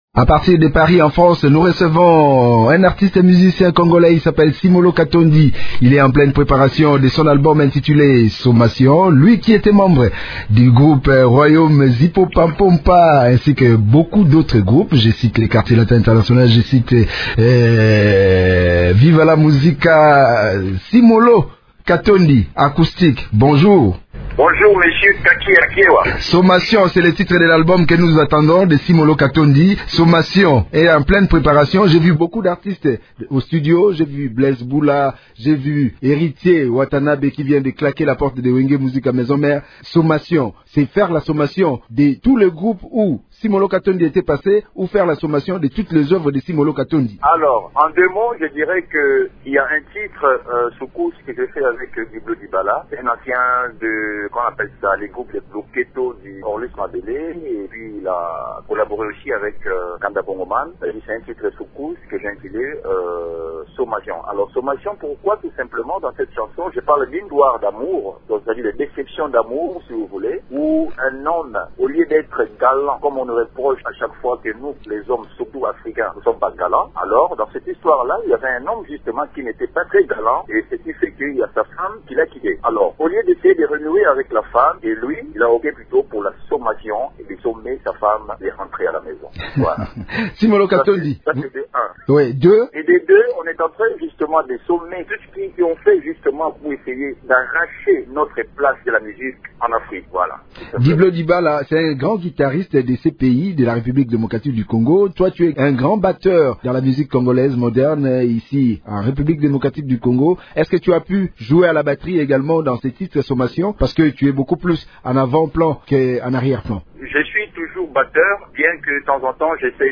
Il l’a dit dans un entretien accordé, le week-end dernier, à Radio Okapi.